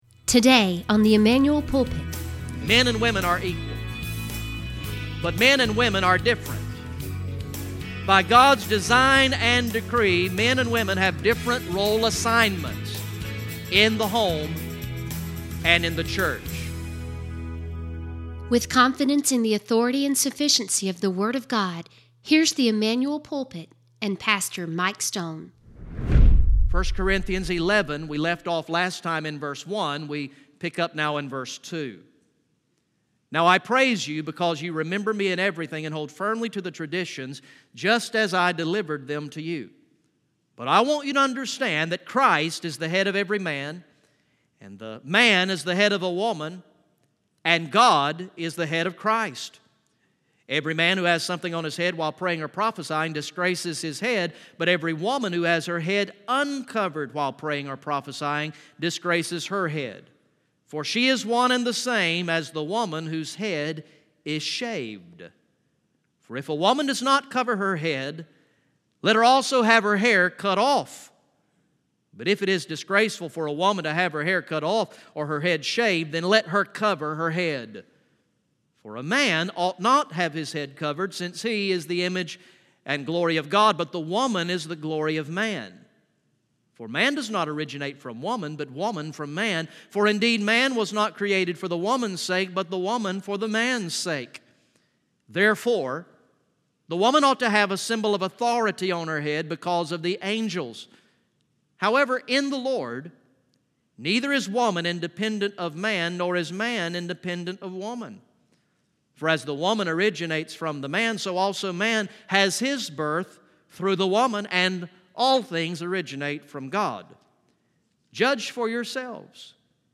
From the morning worship service on Sunday, July 8, 2018